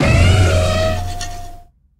Cri de Fort-Ivoire dans Pokémon HOME.